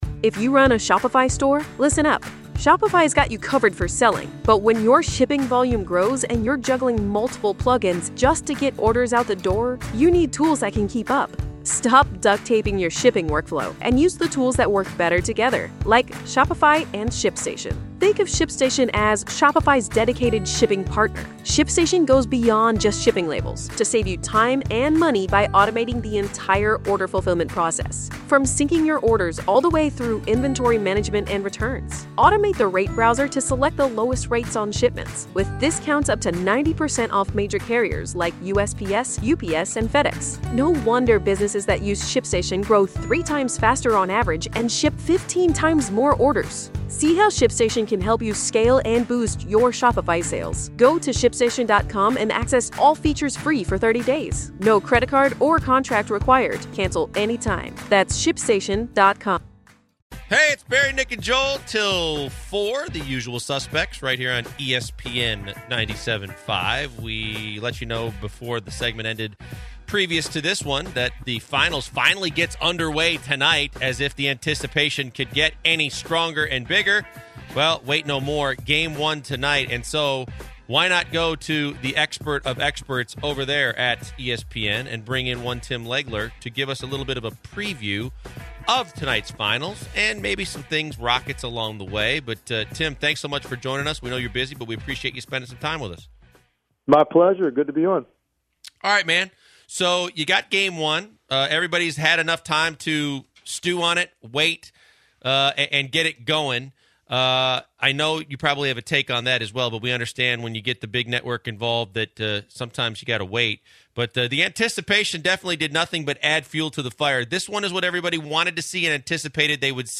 Tim Legler Interview
NBA Basketball insider Tim Legler joins the program to talk about the NBA Finals.